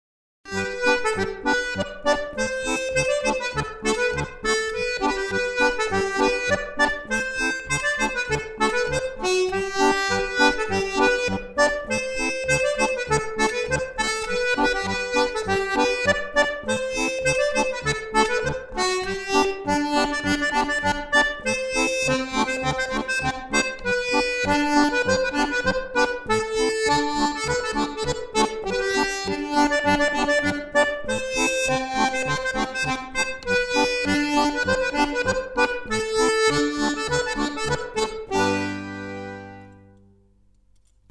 Tabs for chromatic accordion
La semaine des 7 lundis* Scottish à 2 voix Voix 1
Voix 1